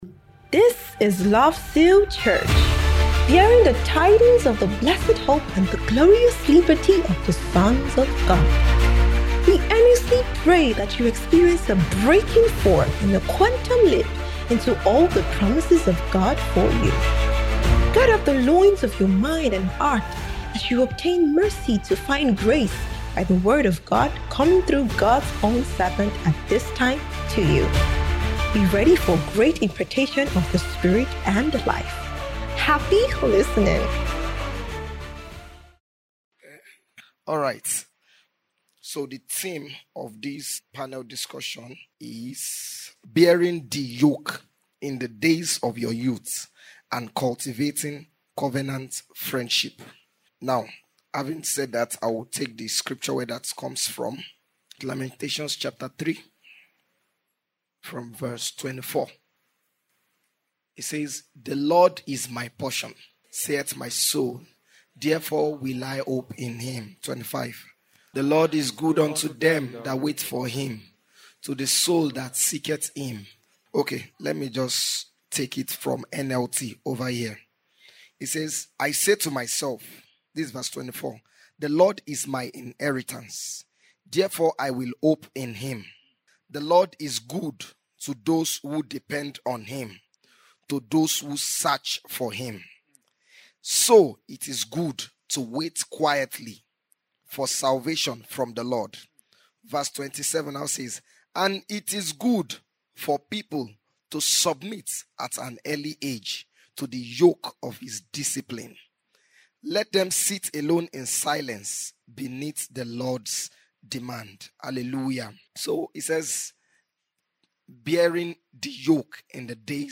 Panel Session - Bearing The Yoke in the days of Your Youth & Cultivating Covenant Friendship
by Panelists on 28th February 2026
Marriageable Singles’ Ingathering